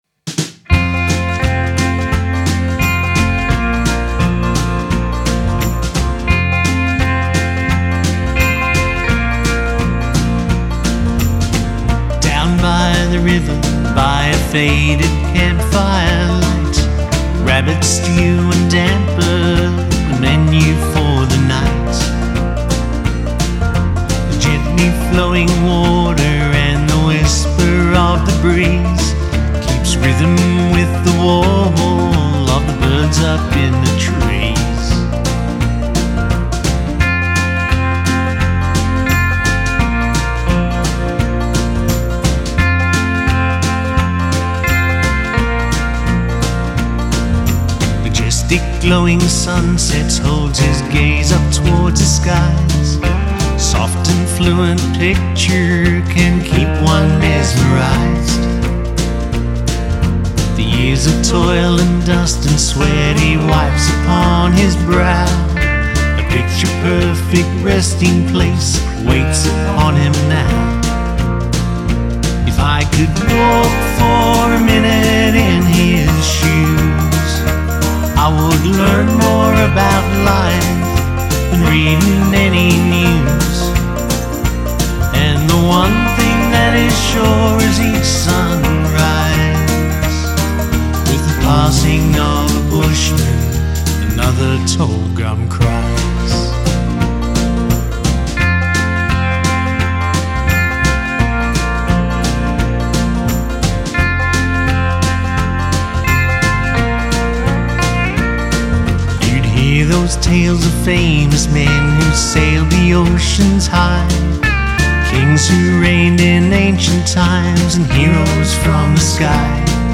Country Music
easy listening and convincing delivery